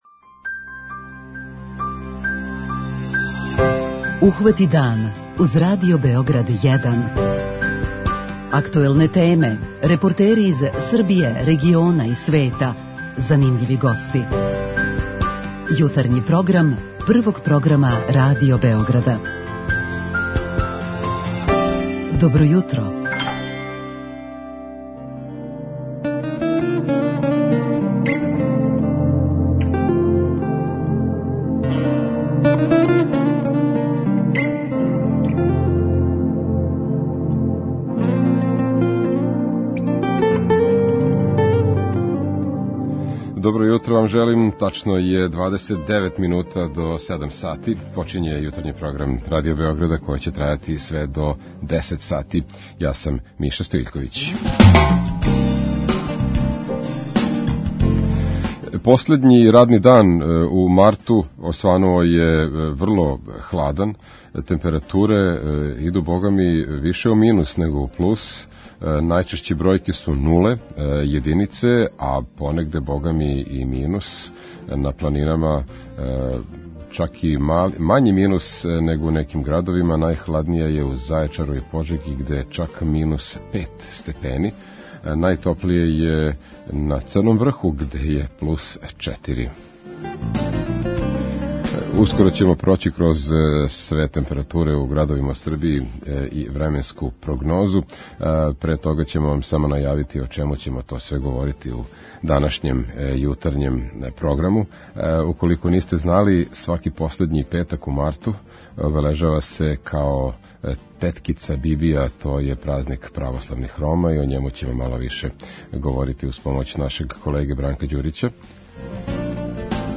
С гостом у студију причаћемо о ромском празнику Теткица Бибија који се прославља сваког последњег месеца у марту.